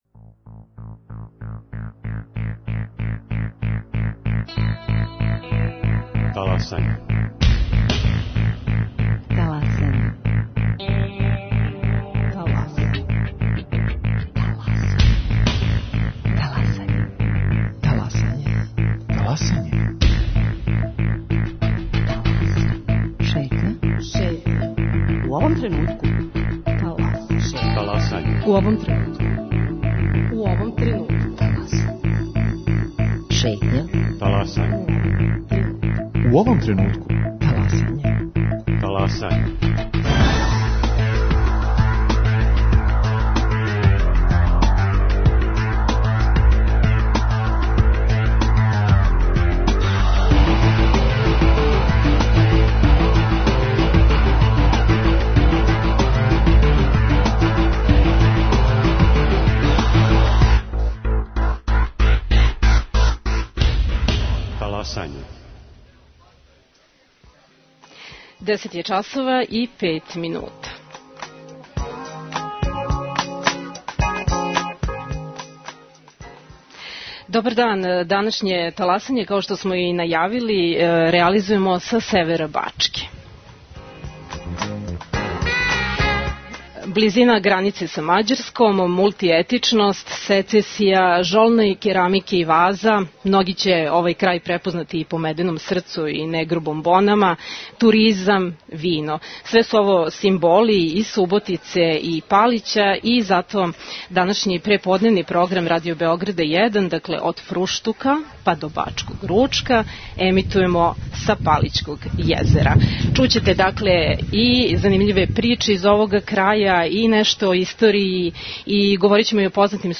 Од фруштука до бачког ручка, - данашњи програм емитујемо са Палићког језера.